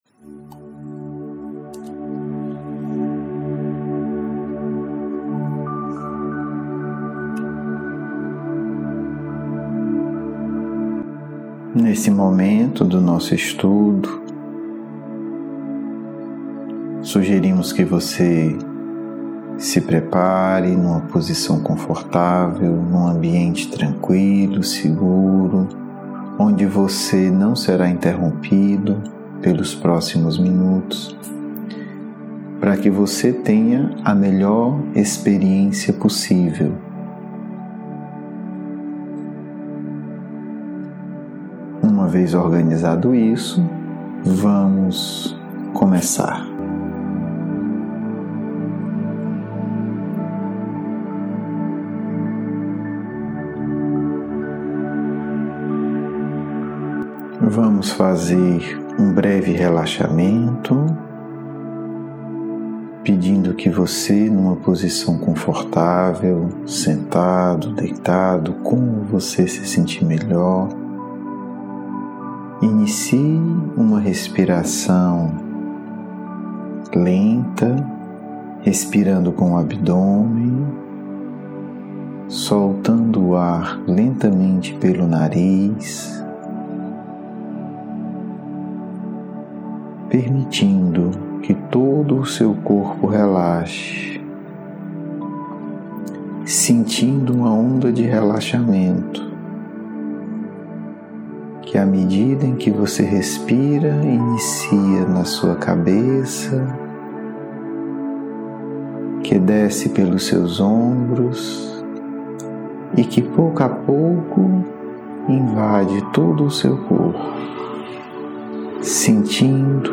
e4-Cristo-visualizacao.mp3